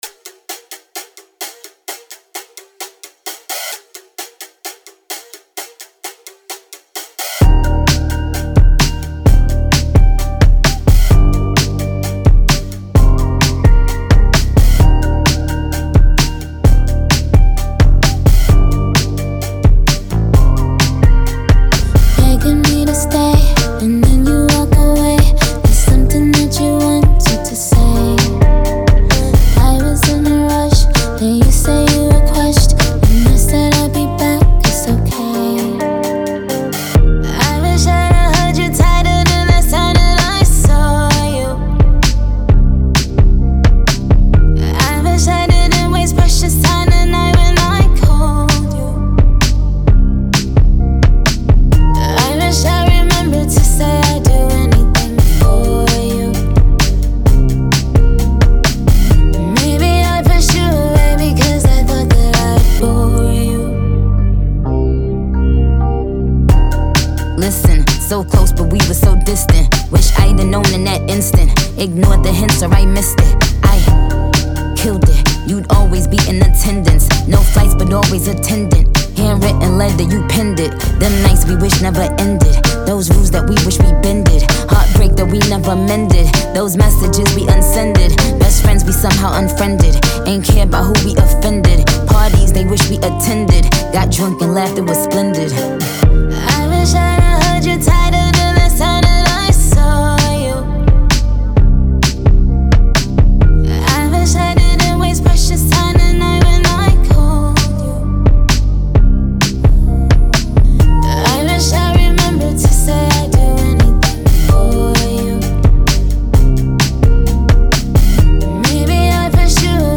Genre : Hip-Hop, Rap